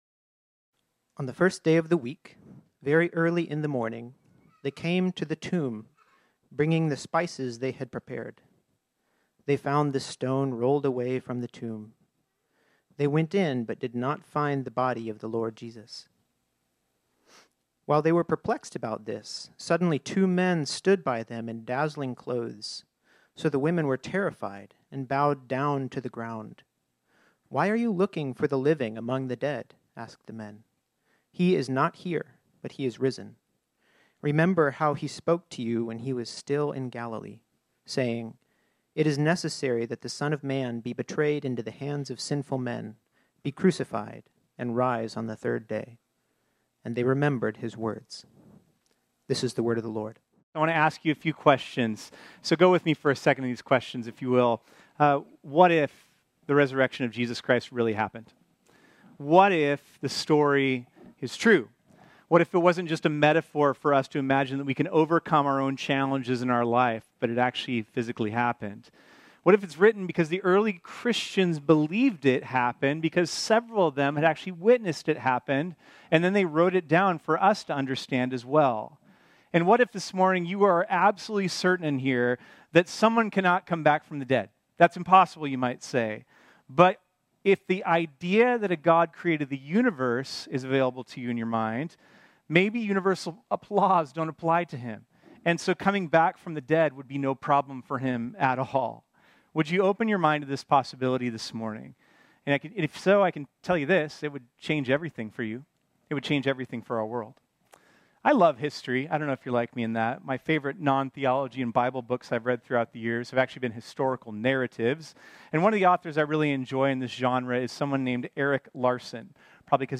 This sermon was originally preached on Sunday, March 31, 2024.